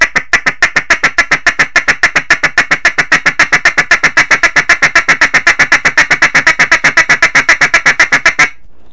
Practice the motion slowly. TI-CA TI-CA TI-CA…TU-CA TU-CA TU-CA…DI-GA DI-GA DI-GA.